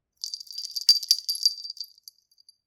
bell2.mp3